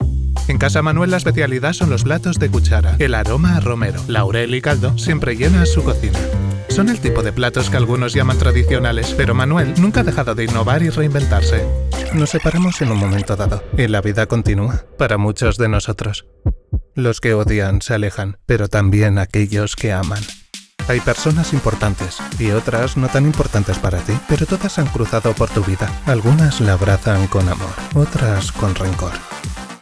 Relaxed Spanish Voice Over Talent
Ad Spanish Demo Reel
Valenciano
Middle Aged